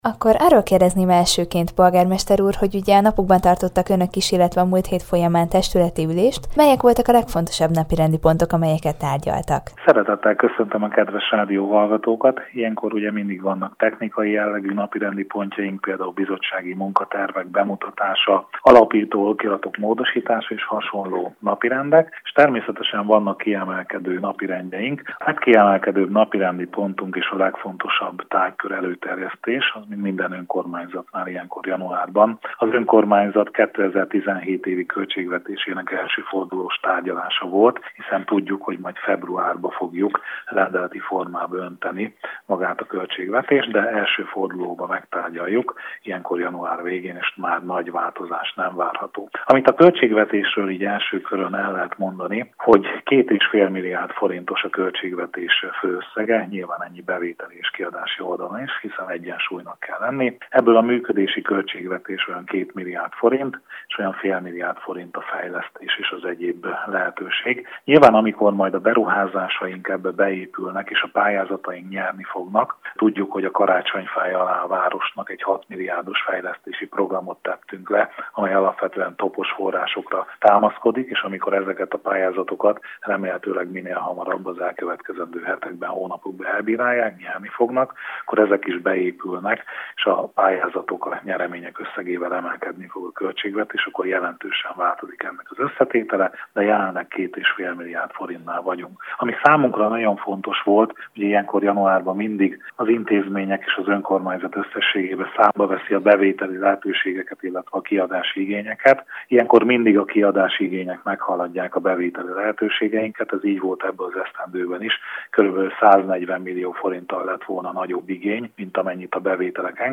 Január 26-án tartotta meg során következő ülését Sarkad képviselő testülete. Mint azt a polgármester tudósítónknak elmondta, a legfontosabb tárgyalt ügy a város költségvetése volt.